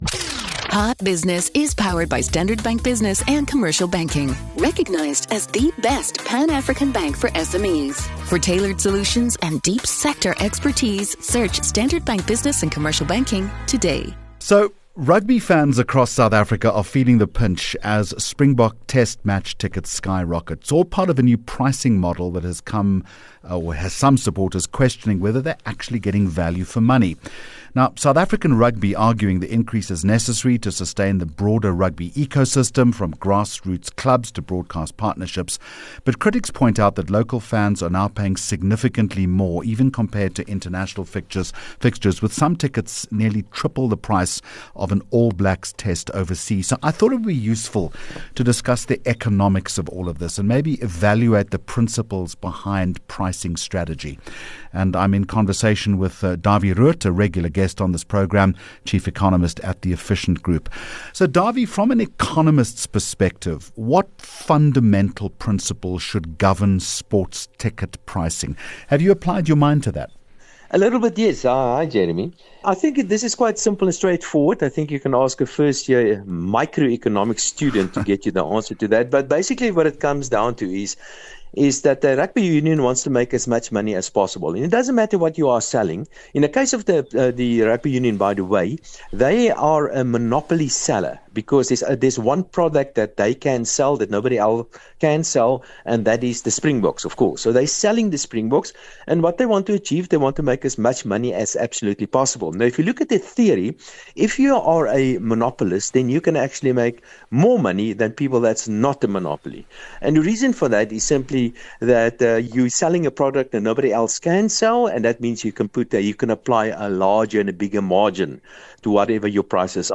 10 Jul Hot Business Interview - Dawie Roodt 10 July 2025